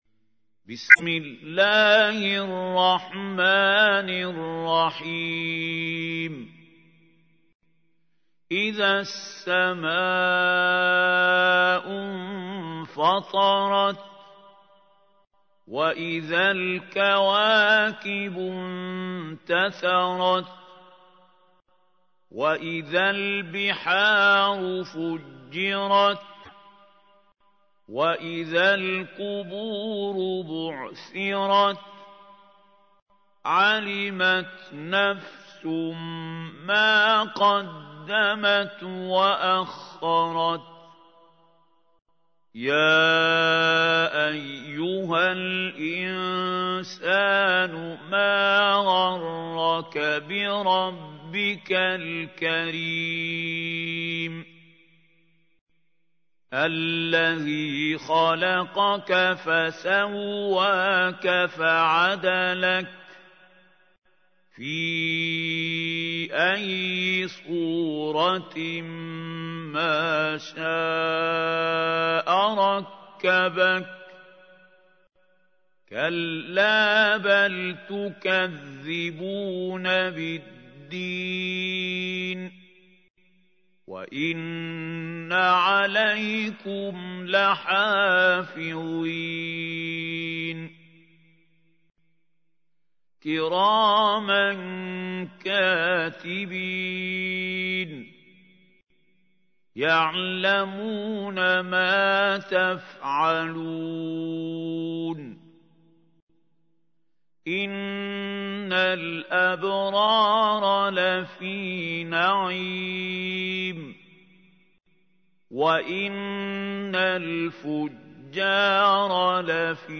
تحميل : 82. سورة الانفطار / القارئ محمود خليل الحصري / القرآن الكريم / موقع يا حسين